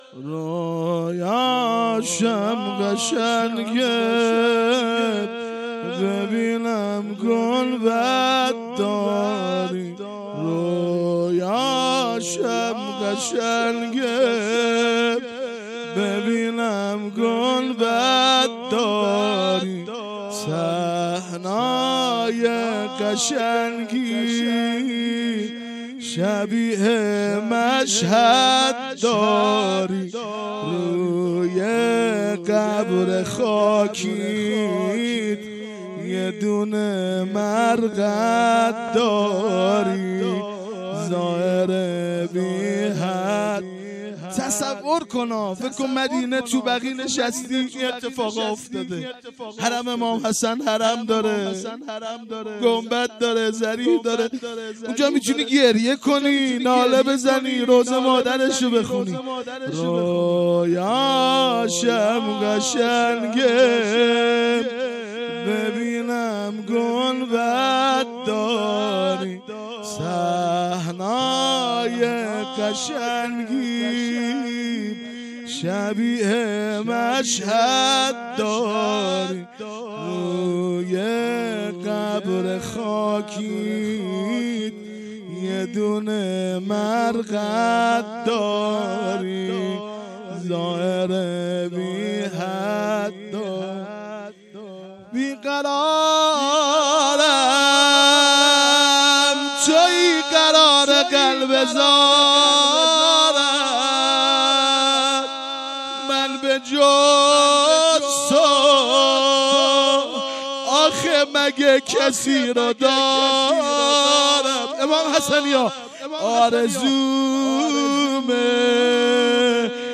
مراسم شهادت امام صادق علیه السلام اردیبهشت ۱۴۰۴